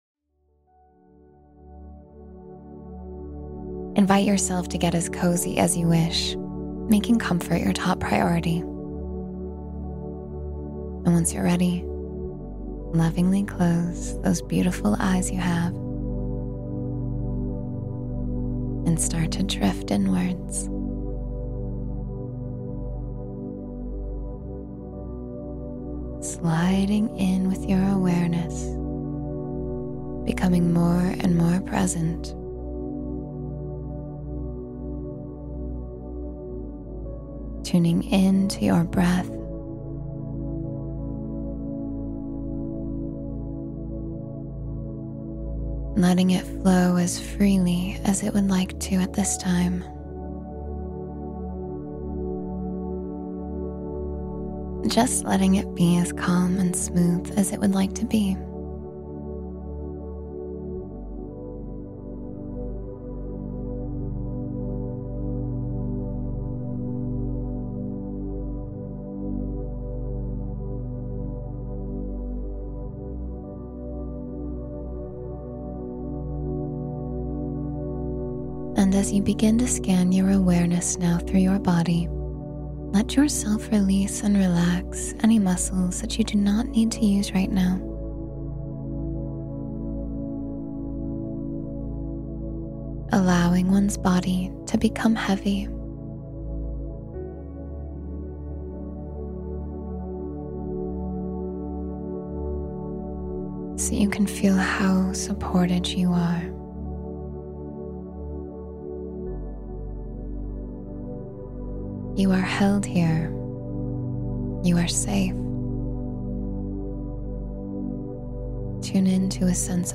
Drift Into Deep Sleep Calmly — Guided Meditation for Restorative Slumber